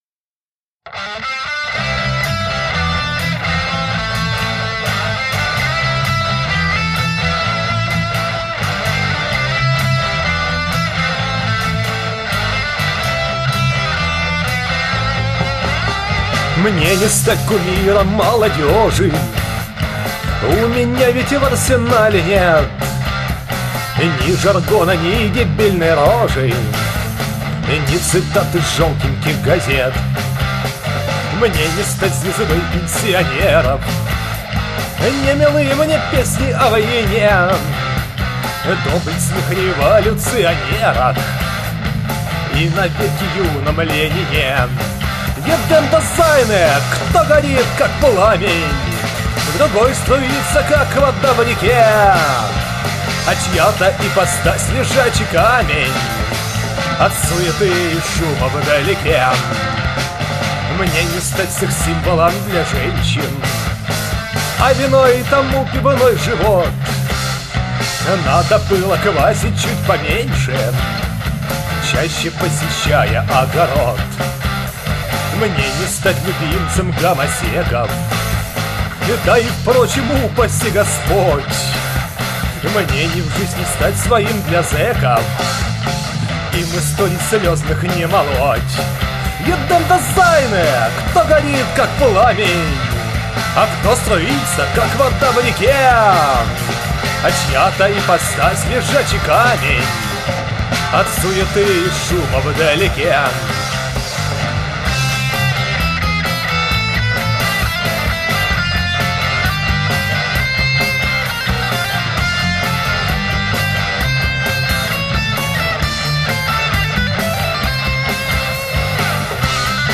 Похоже на Гражданскую Оборону...
Вообще вокал очень неуверенный.